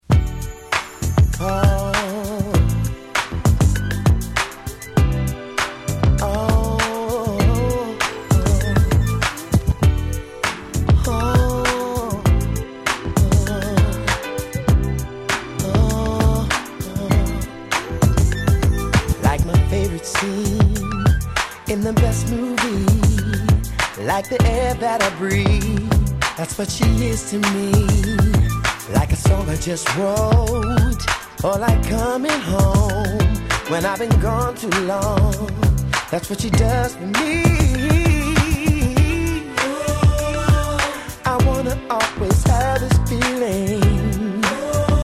03' Smash Hit R&B♪